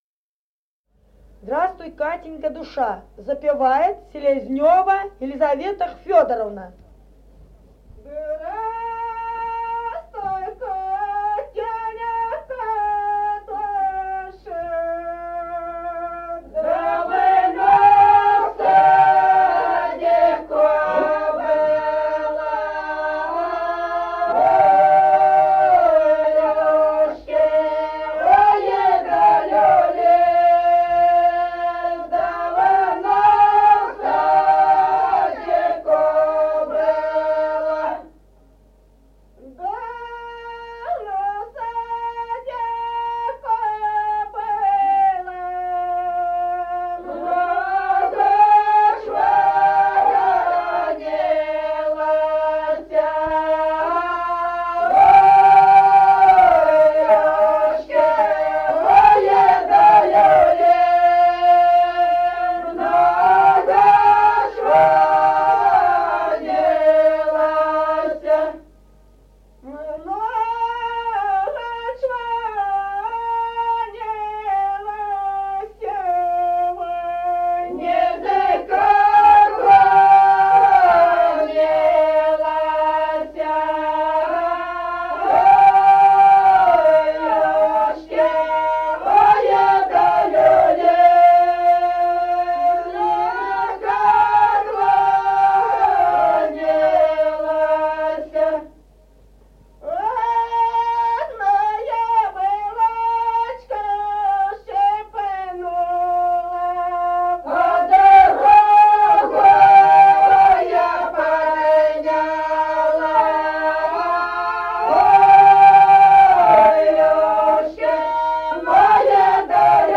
| diskname = Песни села Остроглядово.